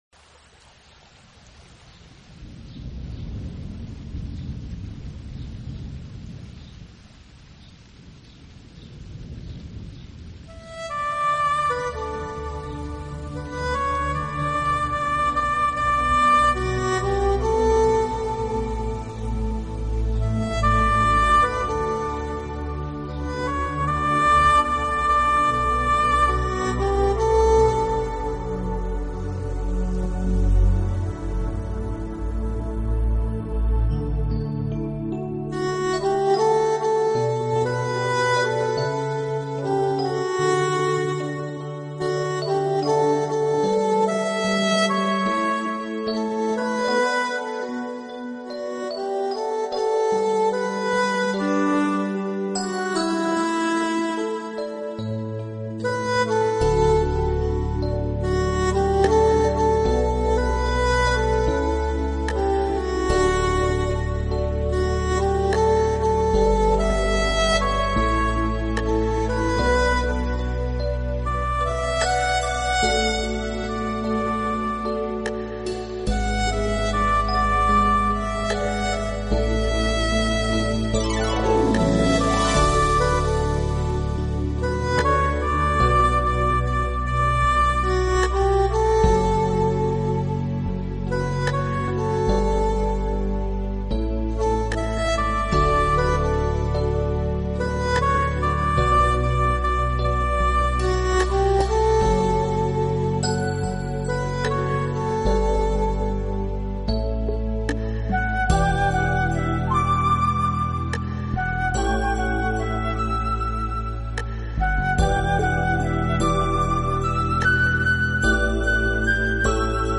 Genre ...........: Meditative